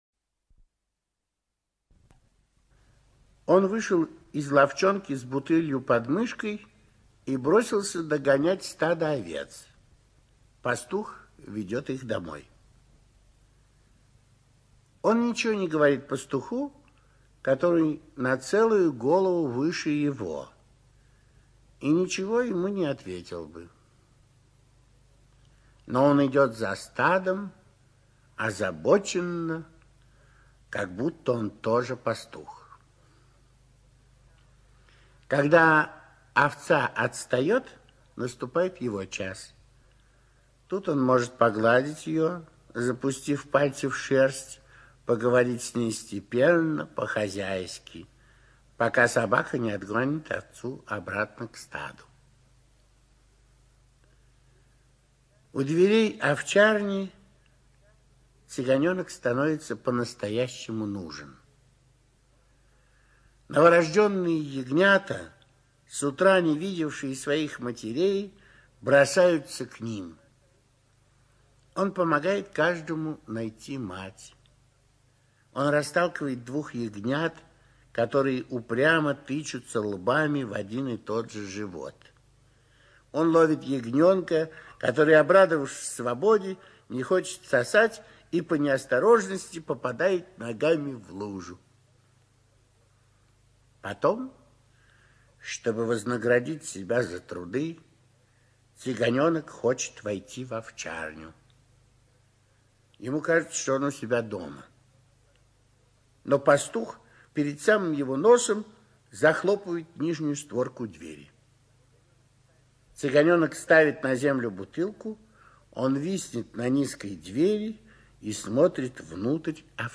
ЧитаетЯншин М.